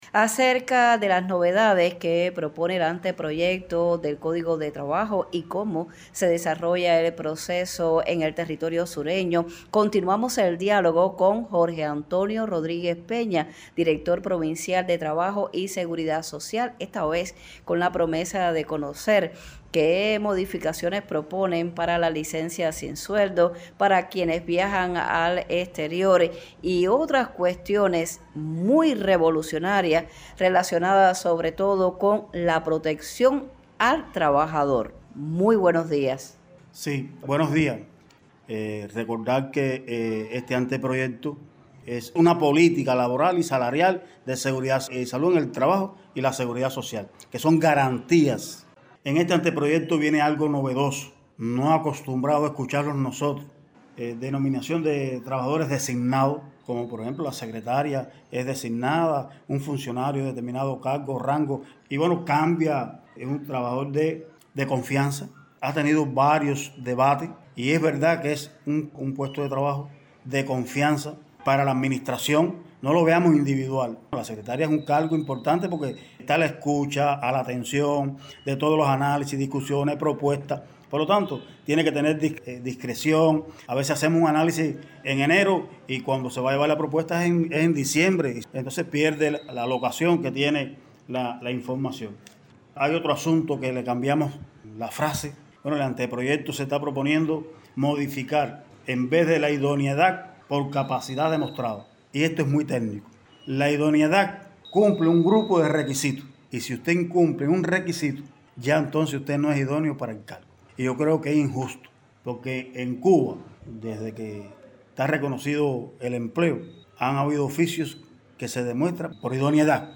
El Anteproyecto del Código de Trabajo propone novedades en relación a la Licencia no retribuida por varias causales, entre ellas consta el derecho a viajar al exterior. El tema, y otros asuntos recurrentes en los colectivos laborales cienfuegueros, centró el diálogo con, Jorge Antonio Rodríguez Peña, director provincial de Trabajo y Seguridad Social.